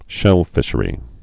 (shĕlfĭshə-rē)